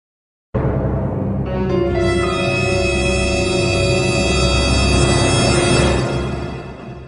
• Качество: 128, Stereo
опасность